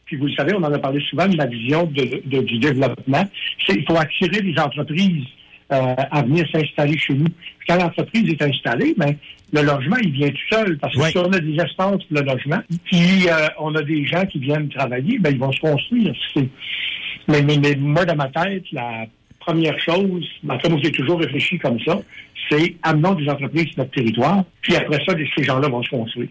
Le maire de l’endroit, Francis Saint-Pierre, a expliqué sur les ondes du FM 93 que quatre projets sont en développement et atteignent différents niveaux de préparation.